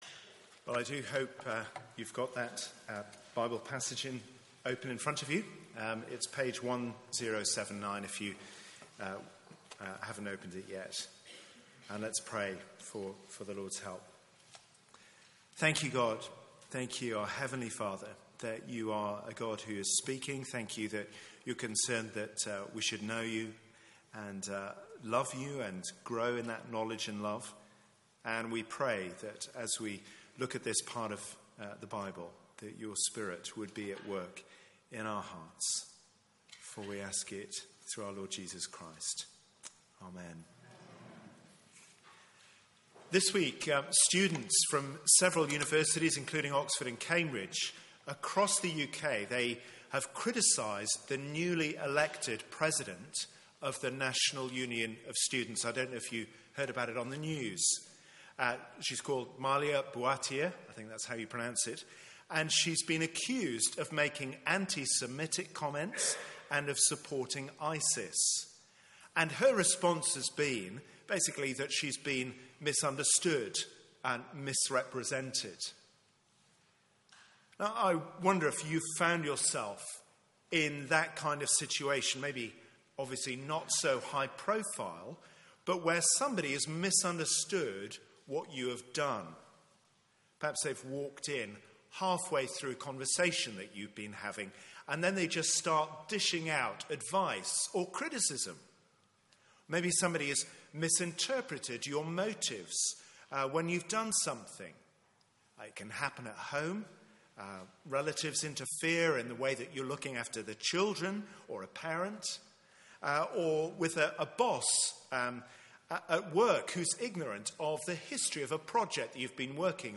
Media for 9:15am Service on Sun 24th Apr 2016 09:15
Series: The gathering storm Theme: Death and devotion Sermon